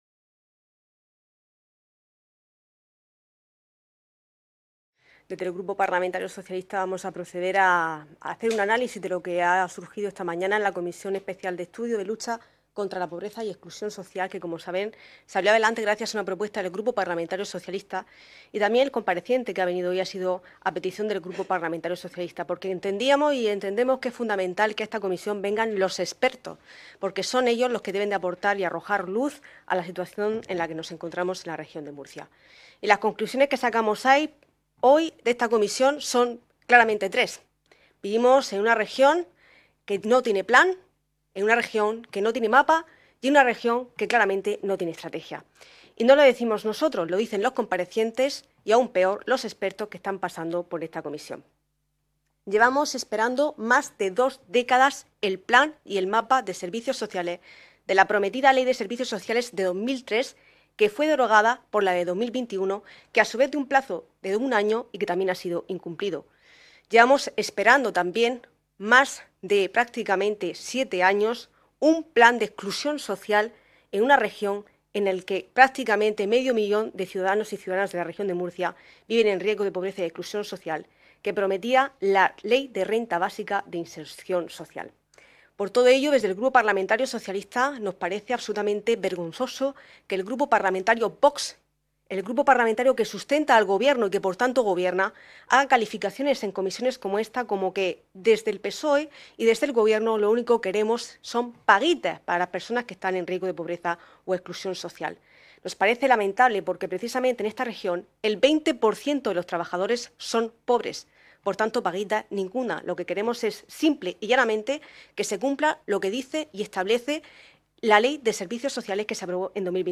Ruedas de prensa posteriores a la Comisión Especial de Estudio de Lucha contra la Pobreza y la Exclusión Social en la Región de Murcia
• Grupo Parlamentario Vox
• Grupo Parlamentario Popular
• Grupo Parlamentario Socialista